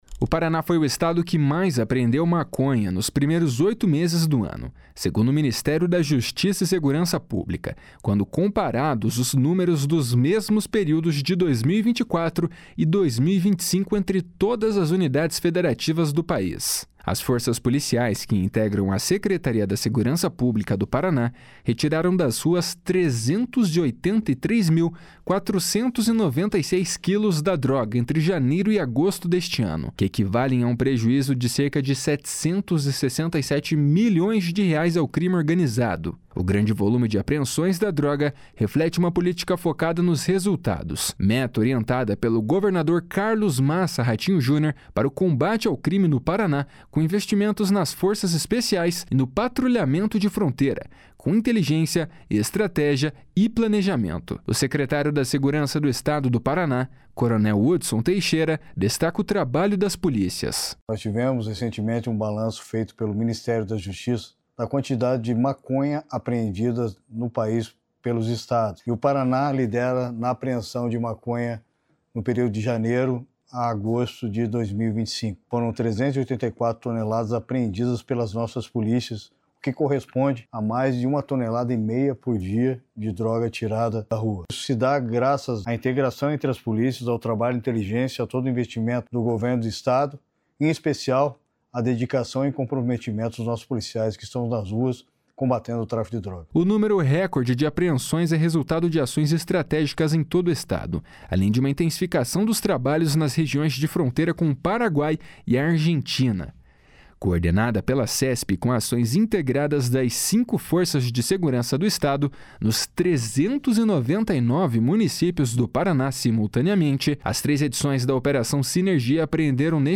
O secretário da Segurança do Estado do Paraná, Coronel Hudson Teixeira, destaca o trabalho das polícias. // SONORA HUDSON TEIXEIRA //